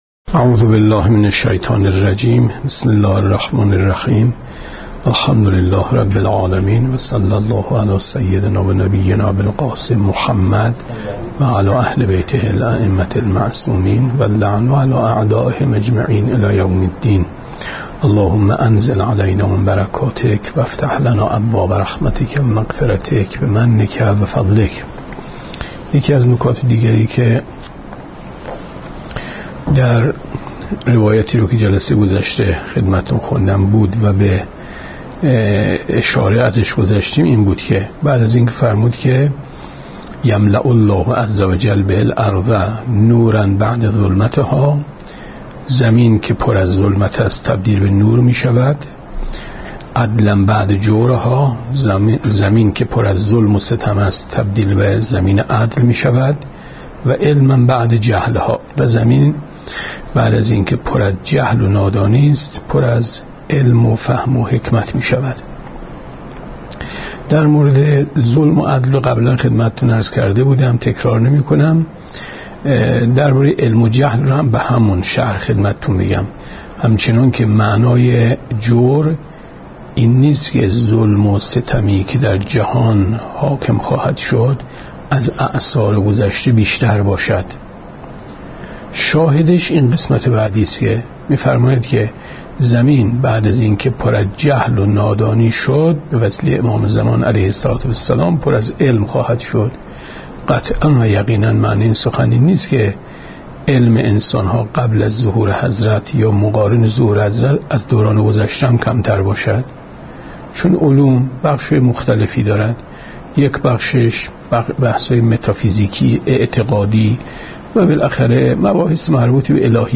گفتارهای ماه مبارک رمضان 1436 ـ جلسه نوزدهم ـ 23/ 4/ 94 ـ شب بیست و هشتم ماه رمضان